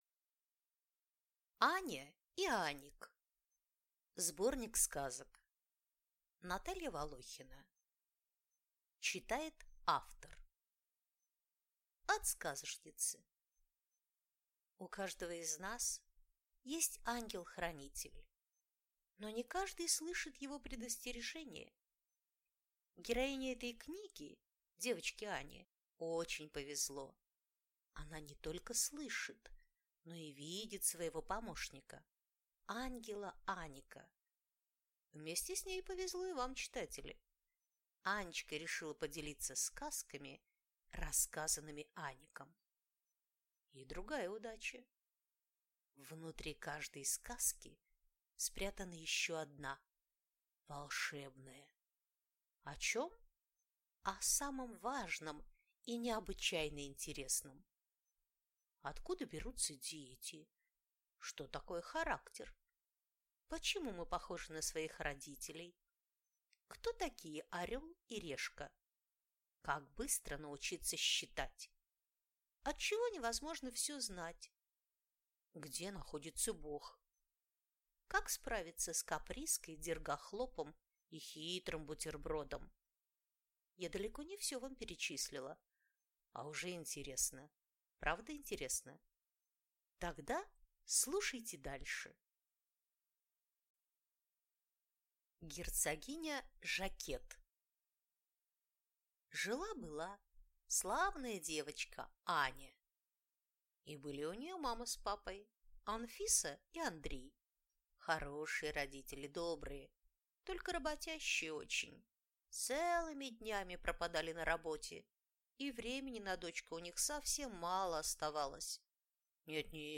Аудиокнига Аня и Аник | Библиотека аудиокниг